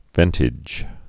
(vĕntĭj)